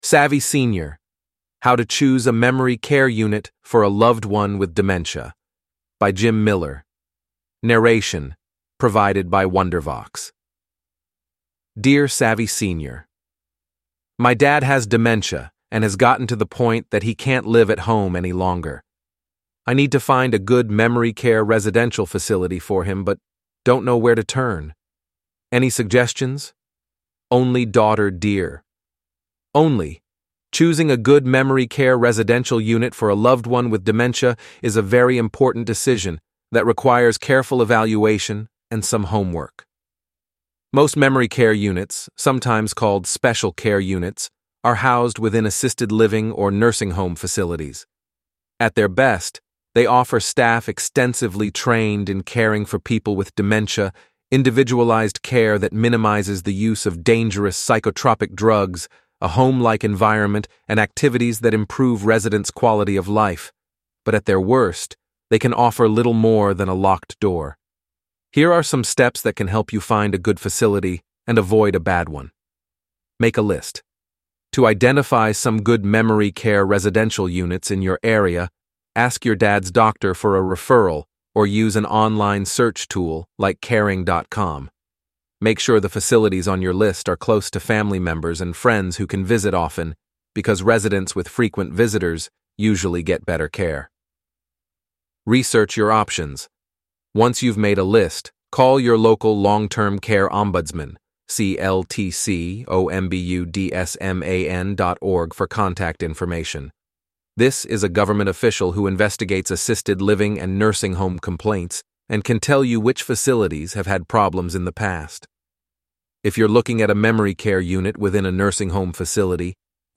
Enjoy this 15-minute interview recorded yesterday.